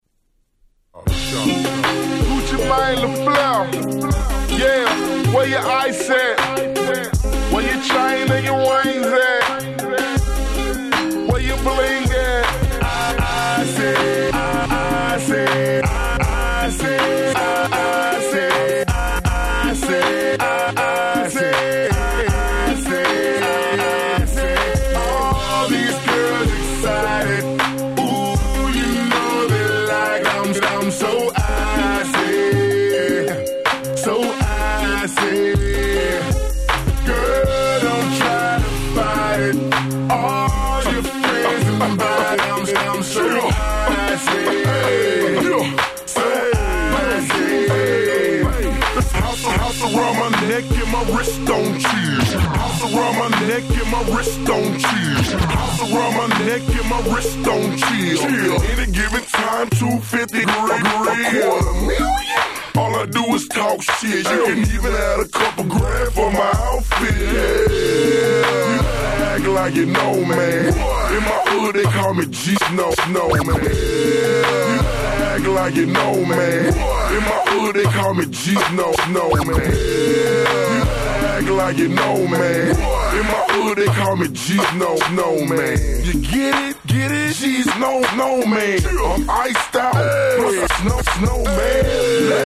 05' Smash Hit Southern Hip Hop !!
しっかりChopped & Screwedバージョンが収録されております！！